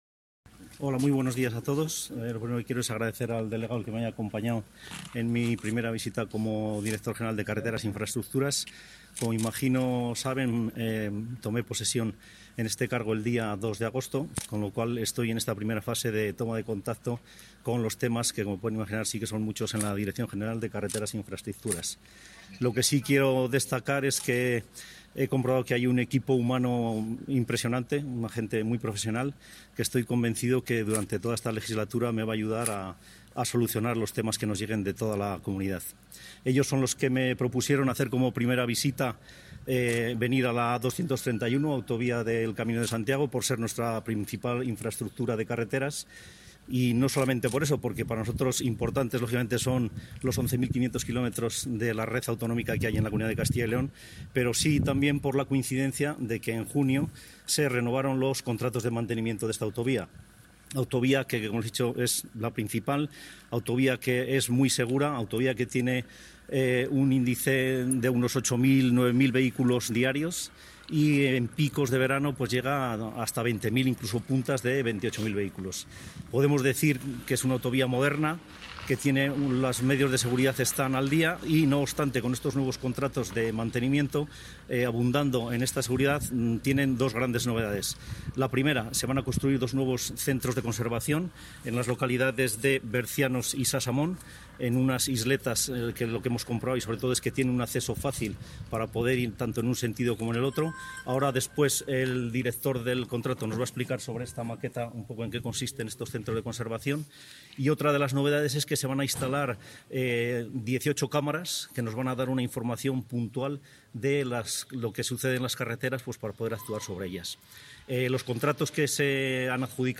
Intervención del director general de Carreteras e Infraestructuras.